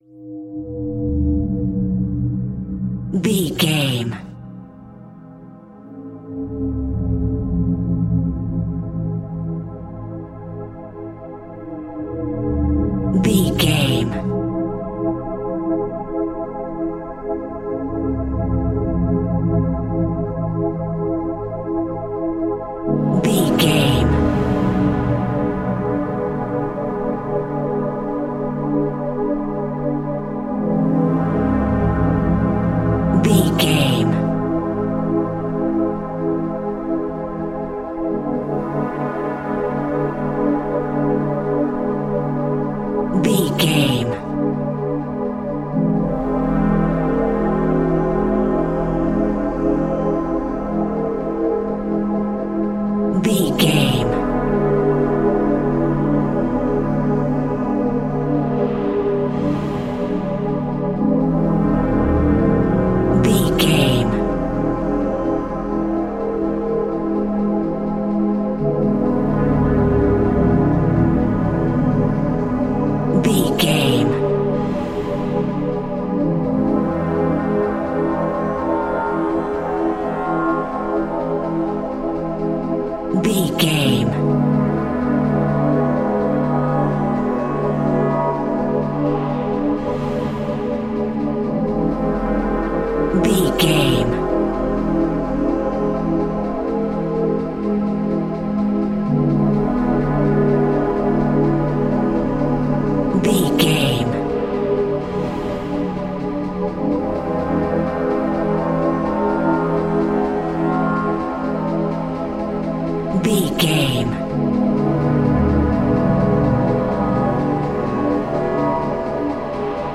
Atonal
ominous
haunting
eerie
synthesizer
percussion
mysterious
horror music
Horror Pads
Horror Synths